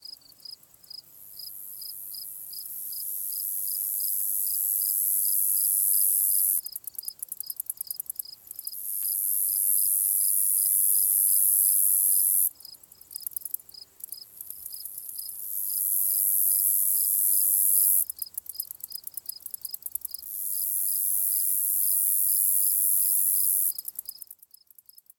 insectday_14.ogg